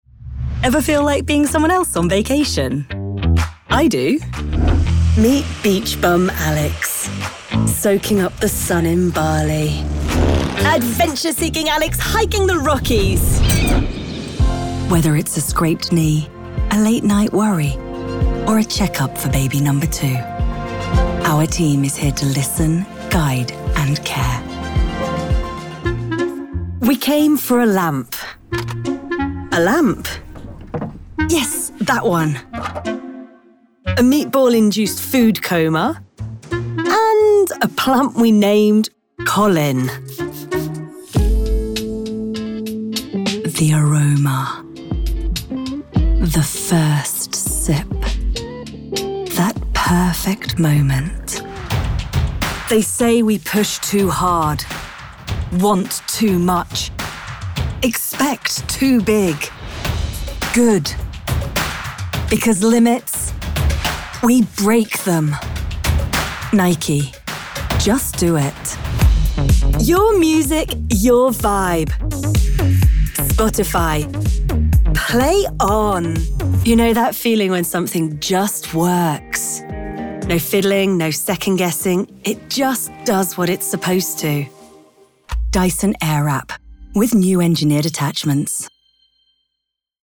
Engels (Brits)
Commercieel, Jong, Natuurlijk, Vriendelijk, Zakelijk
Commercieel